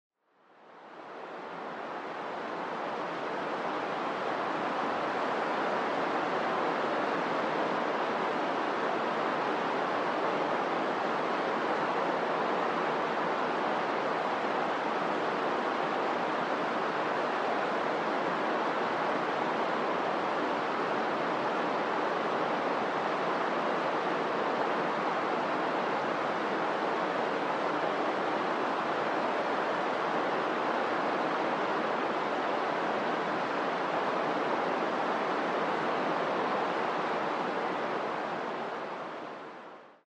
12 Hours of White Noise sound effects free download